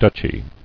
[duch·y]